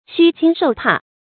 躭惊受怕 xīn jīng shòu pà 成语解释 犹言担受惊吓。